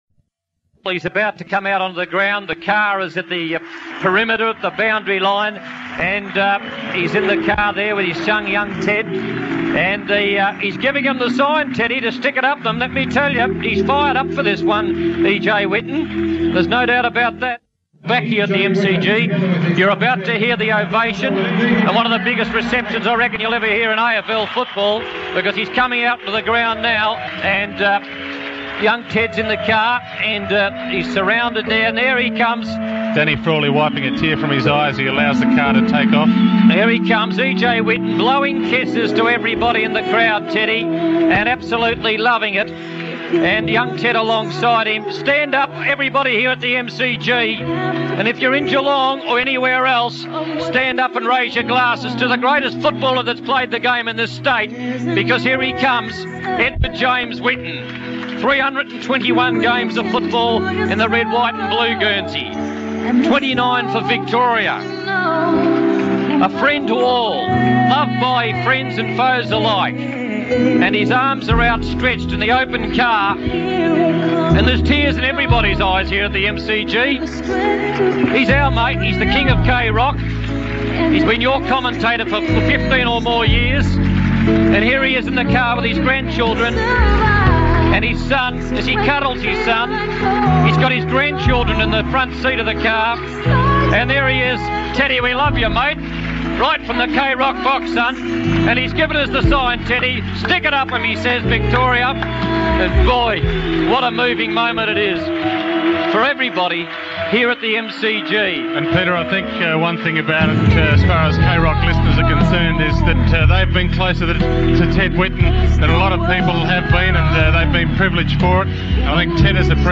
25 years after Ted Whitten farewelled the public, we bring you K rock Football's broadcast of his final lap of the MCG.